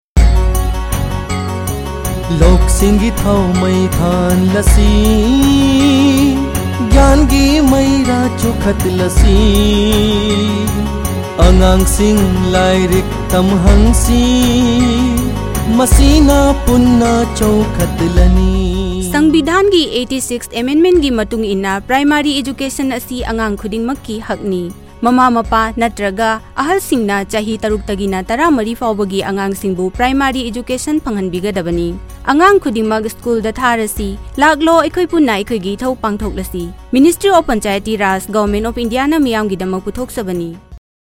145 Fundamental Duty 11th Fundamental Duty Duty for all parents and guardians to send their children in the age group of 6-14 years to school Radio Jingle Manipuri